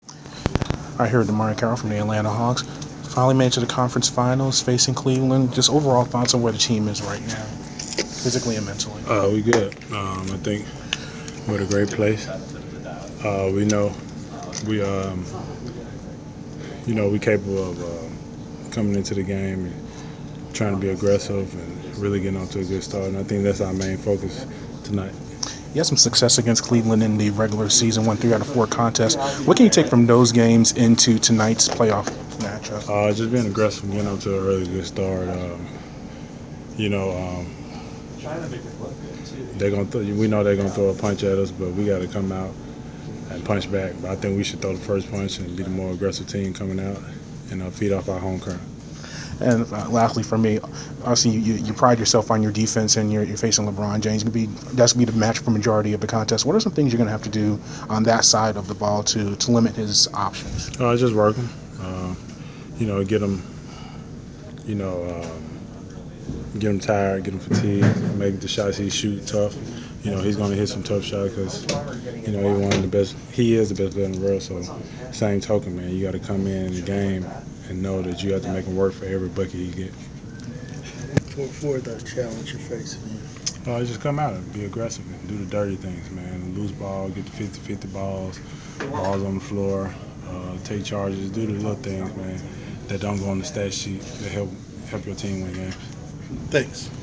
Inside the Inquirer: Pregame interview with Atlanta Hawks DeMarre Carroll 5/20/15
The Sports Inquirer caught up with Atlanta Hawks’ forward DeMarre Carroll before his team’s home playoff contest against the Cleveland Cavs in the Eastern Conference finals on May 20. Topics included the Hawks’ preparation for the contest and Carroll’s primary responsibility of defending LeBron James.